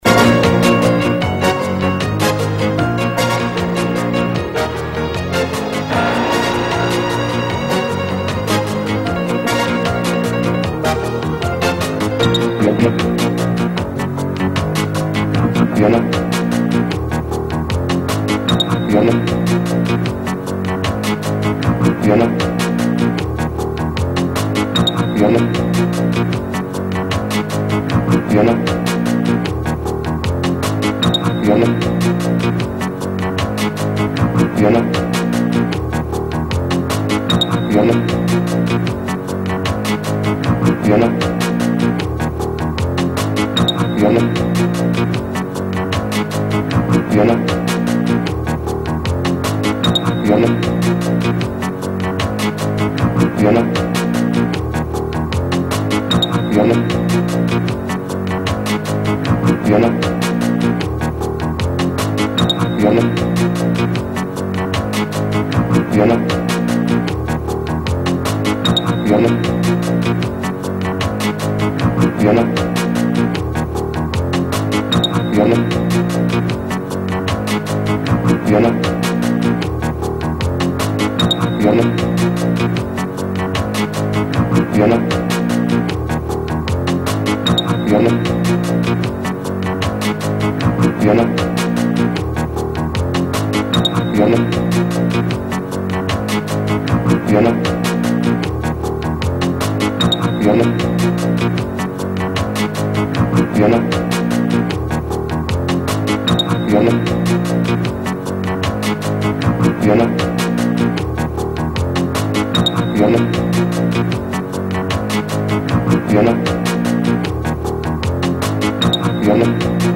le bruit coincoin lol